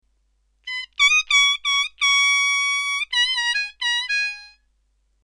diatonic harmonica